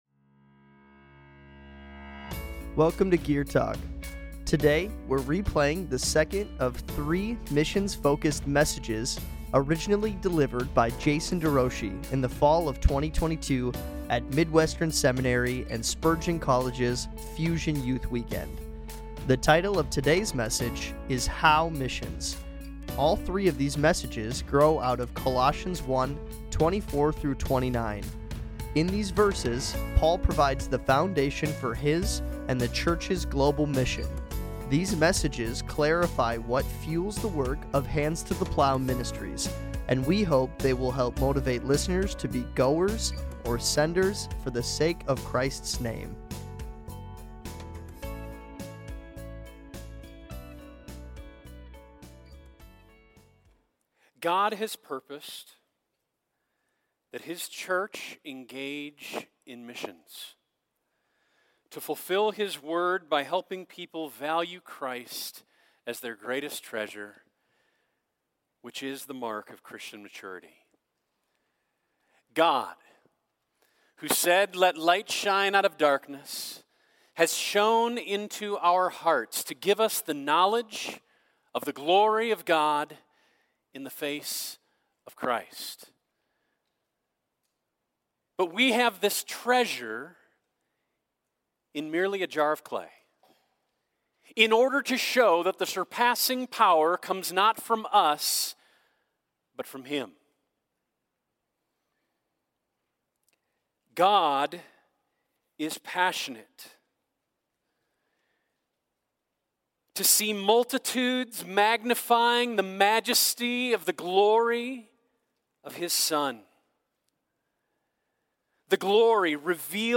Fusion Youth Weekend 2022